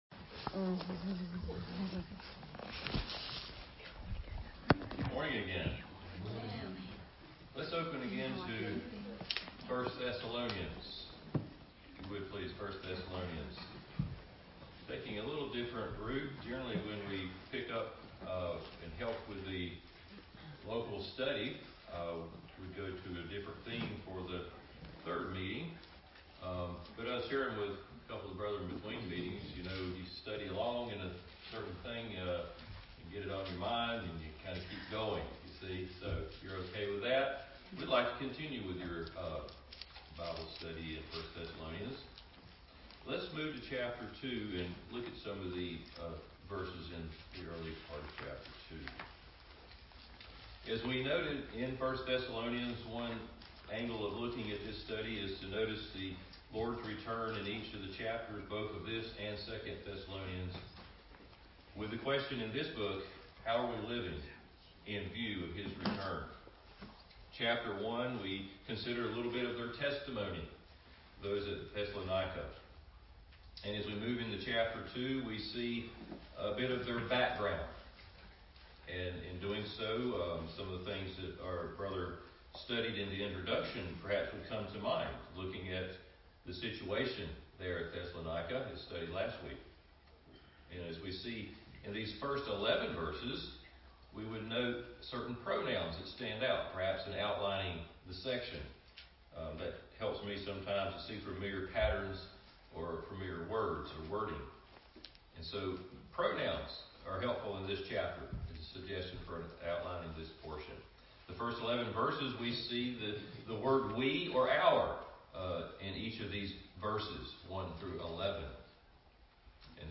Service Type: Family Bible Hour